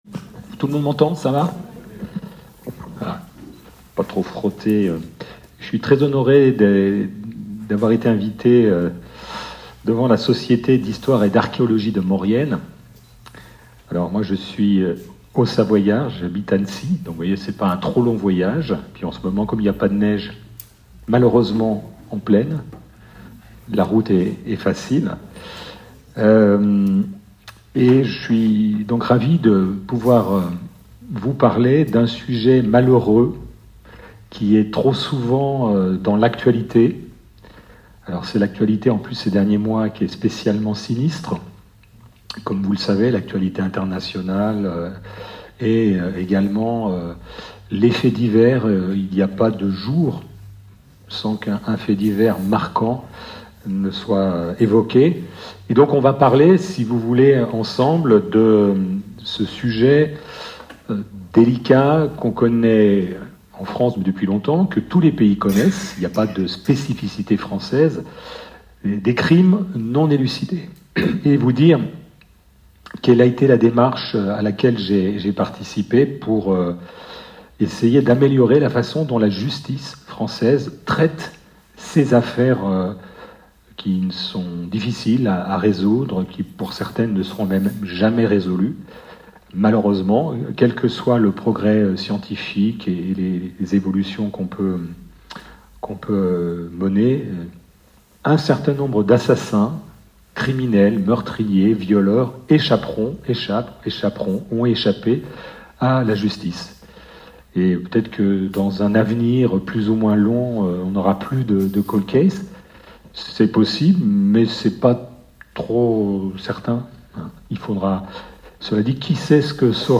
Enregistrement audio de la conférence du 13 décembre 2023 : (1:11:02)